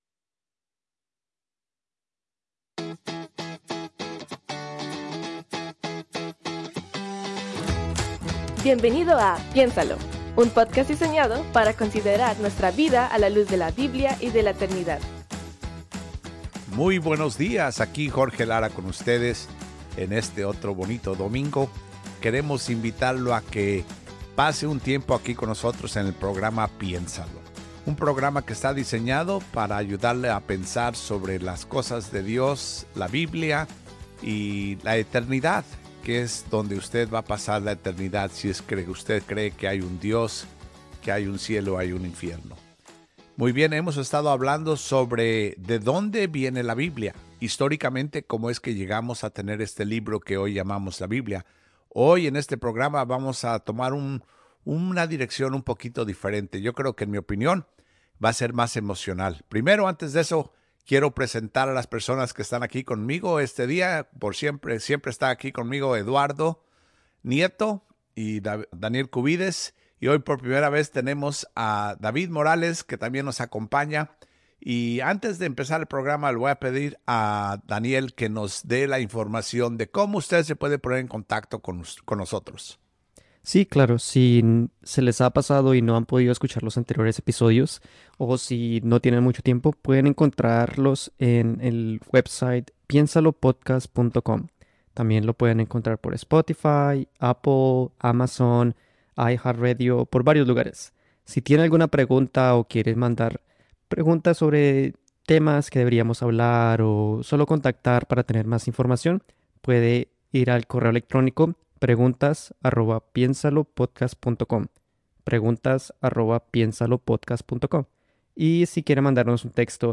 conversan en torno al contenido de la Biblia, abordanto el Antiguo Testamento